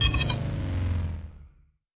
gauss_precision_stop.wav